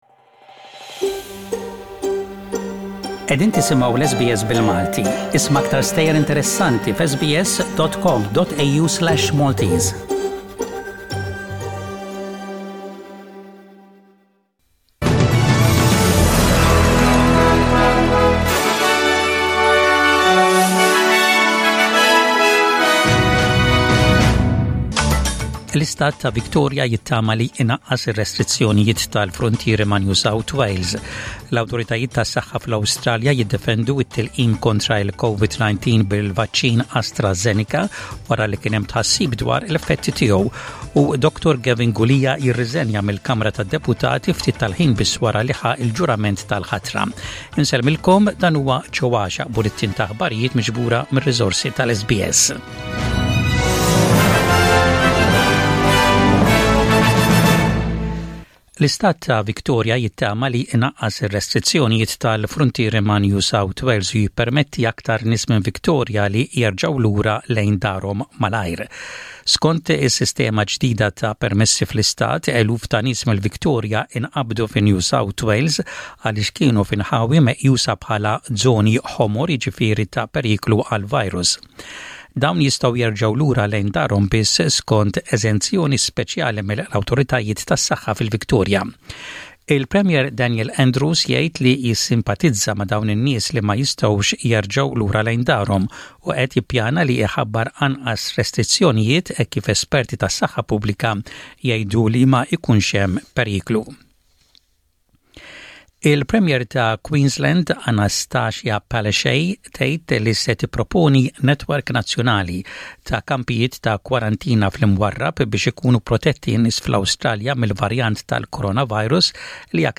SBS Radio | Maltese News: 15/01/21